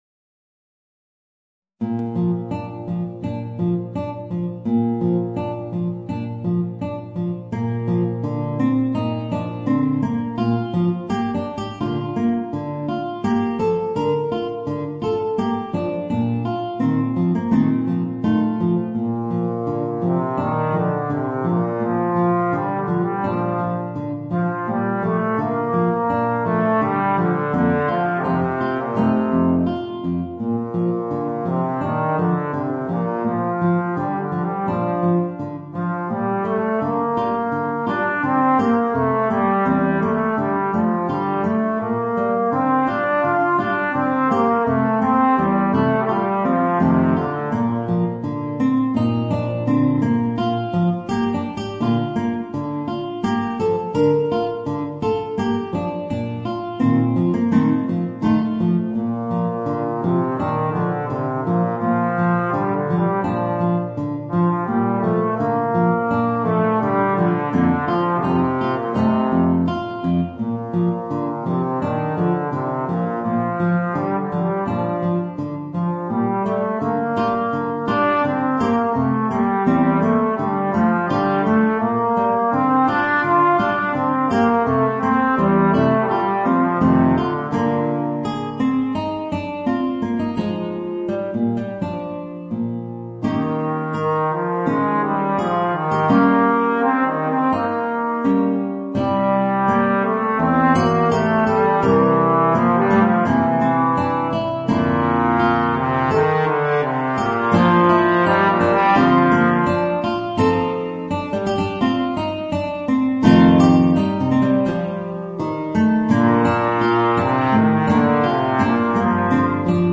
Voicing: Trombone and Guitar